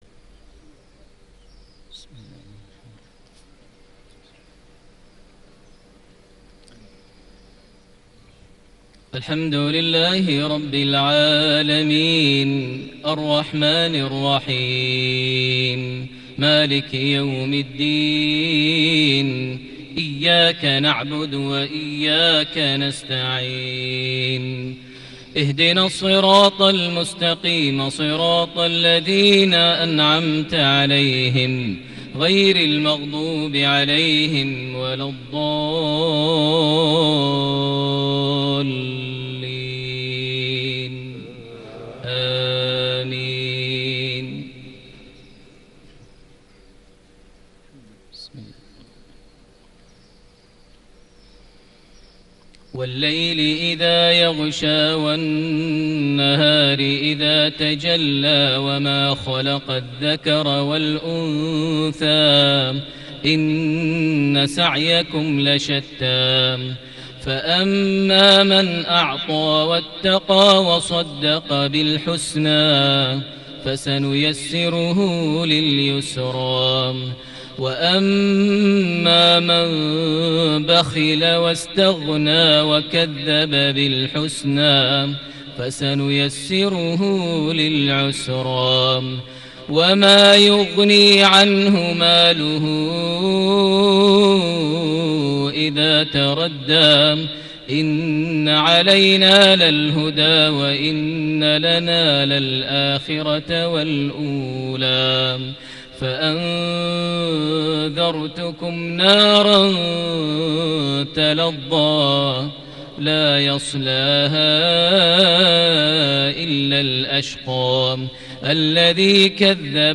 صلاة المغرب ٩ صفر ١٤٤٠هـ سورتي الليل - الهمزة > 1440 هـ > الفروض - تلاوات ماهر المعيقلي